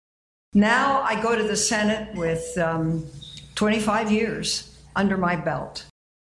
Incumbent Senator Dianne Feinstein will maintain her seat in Congress. Feinstein said during a victory speech Tuesday night the state needs strong representation.